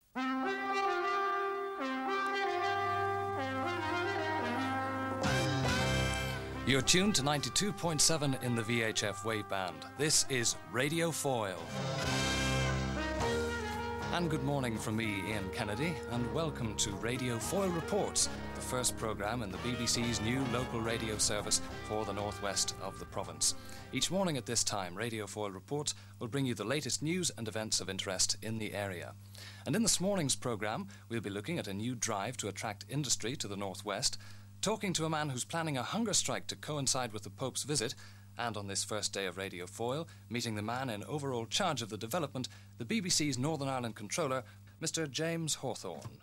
The first ever broadcast from BBC Radio Foyle